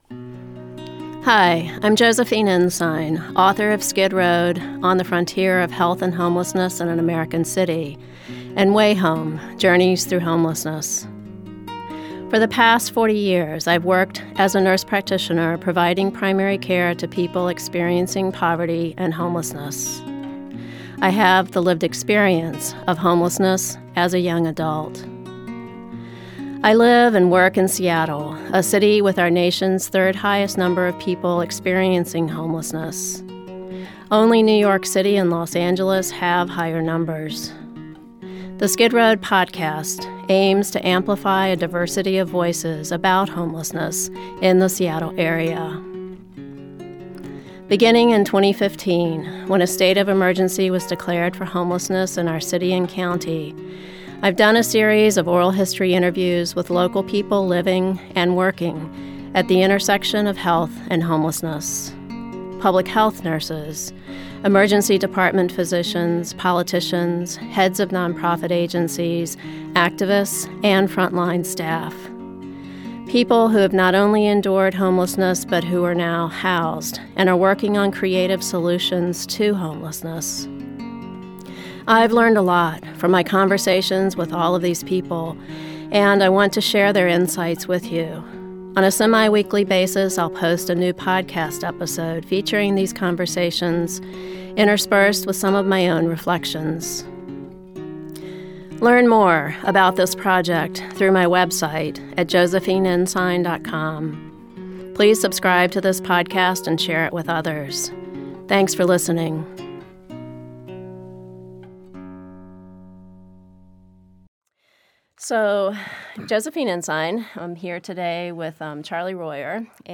A Conversation with Charles Royer (1939-2024)
Charles ("Charley") Royer, Jack Straw Cultural Center, June 21, 2017 Seattle's longest-running mayor (1978-1990), Charles Royer, died this week at his home in Oregon.